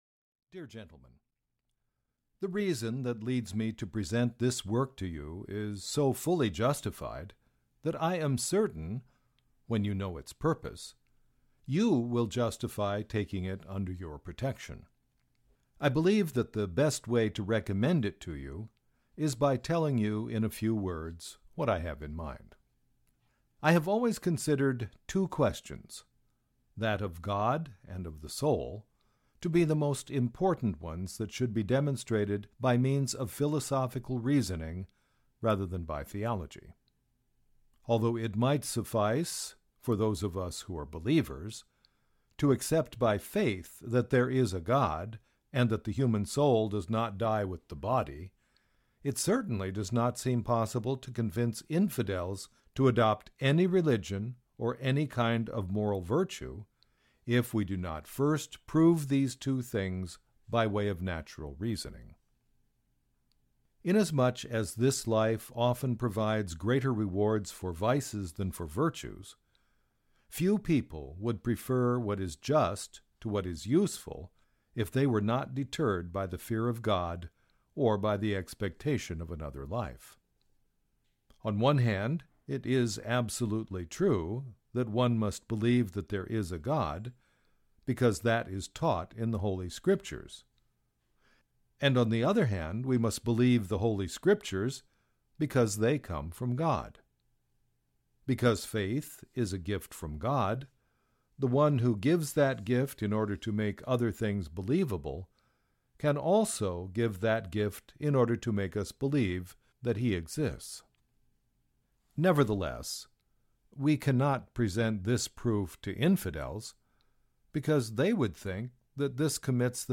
Audio knihaDescartes’ Meditations on First Philosophy (EN)
Ukázka z knihy